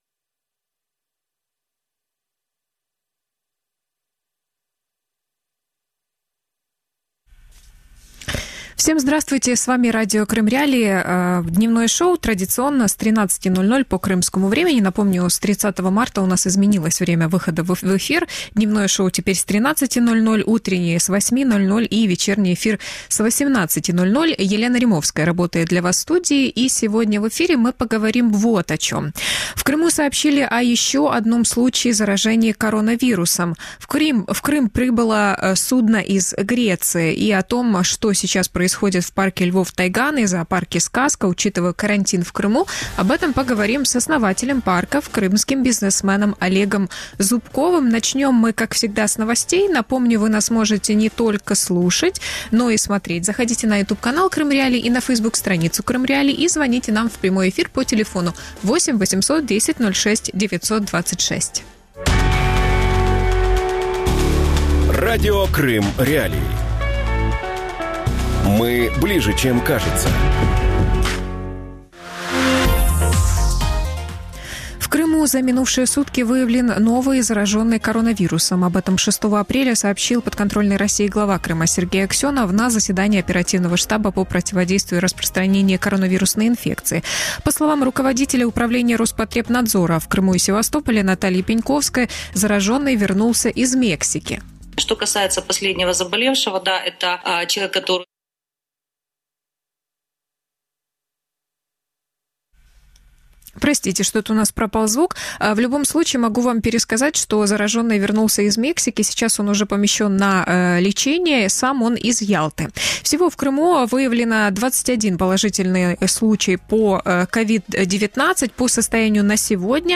После СИЗО на карантине | Дневное ток-шоу